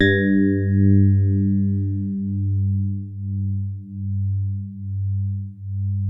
FINE HARD G1.wav